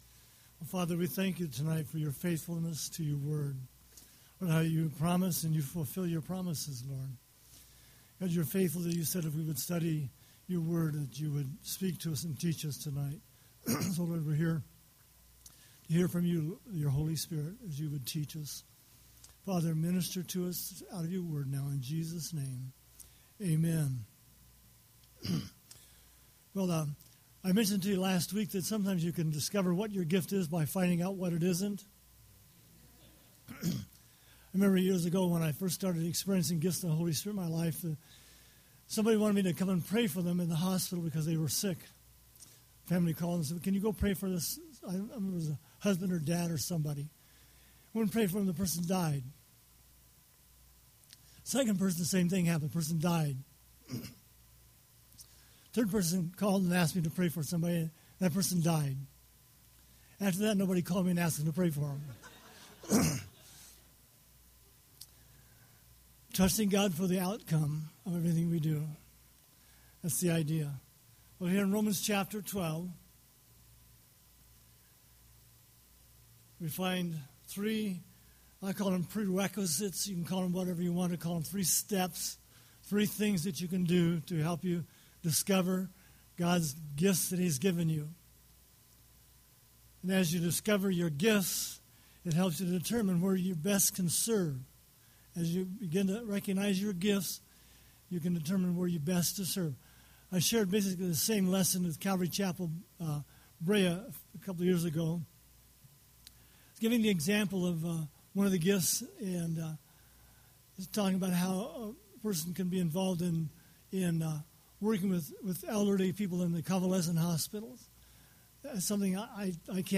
Power of the Holy Spirit Service: Sunday Evening %todo_render% « Life